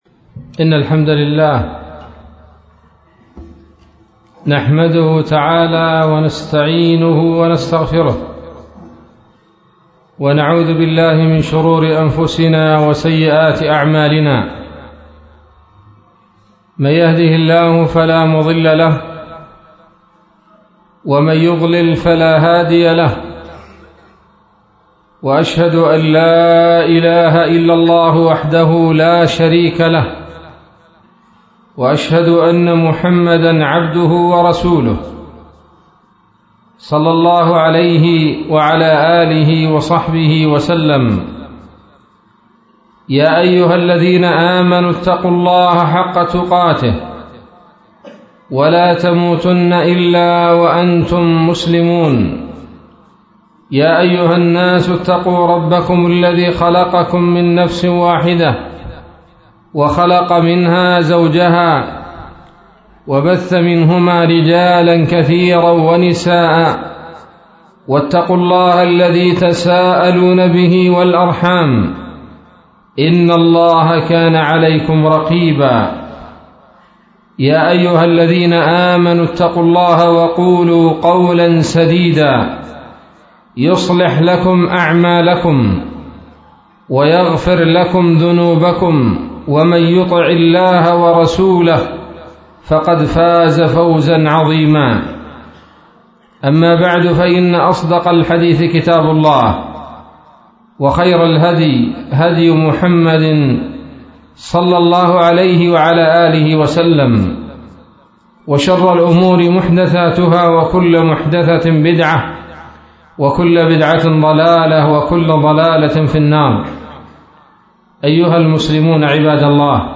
محاضرة-بعنوان-عمارة-المساجد.mp3